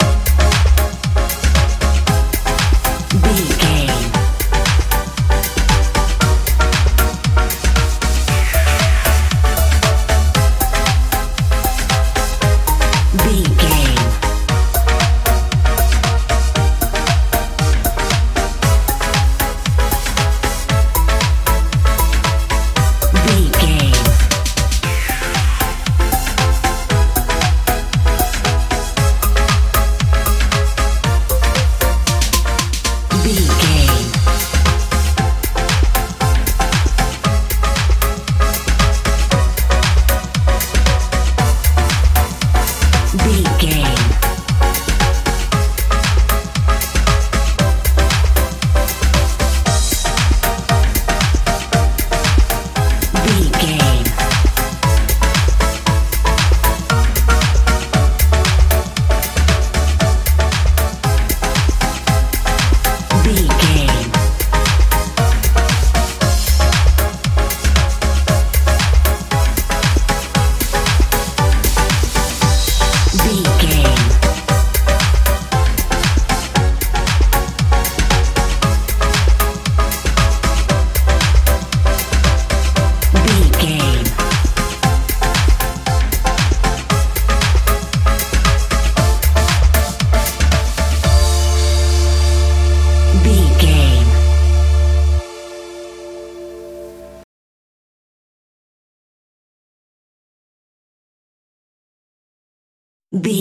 house feel
Ionian/Major
A♭
groovy
funky
synthesiser
bass guitar
drums
80s
strange
inspirational
suspense